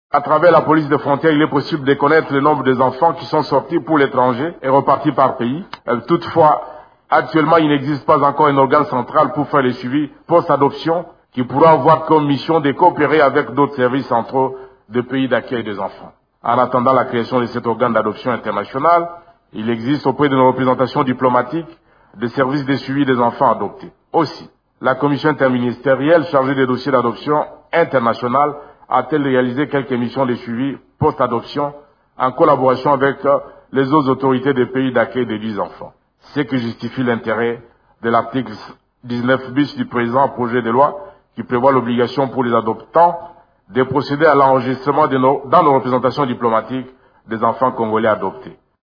Il a fait cette suggestion mercredi 6 avril, lors de sa réplique aux questions des sénateurs sur ce sujet.
Suivez le vice-premier ministre dans cet extrait.